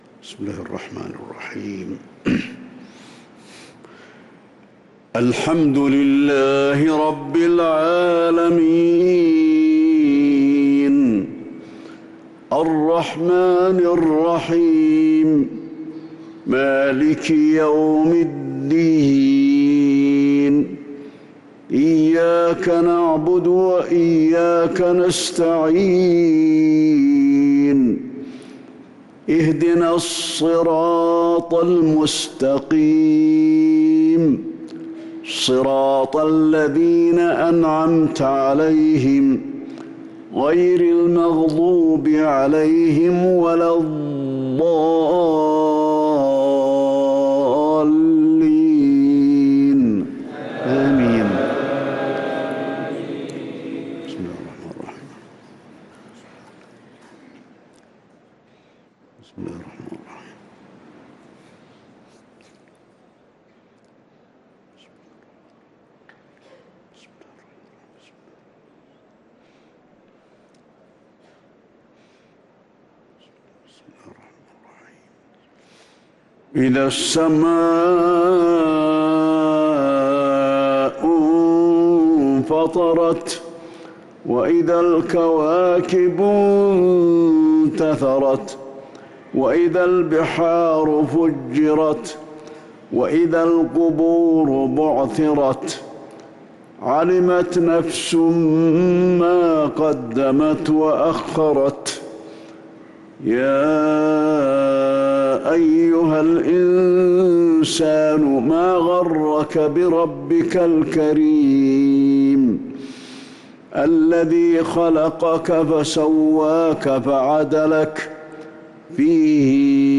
صلاة العشاء للقارئ علي الحذيفي 5 ربيع الآخر 1445 هـ
تِلَاوَات الْحَرَمَيْن .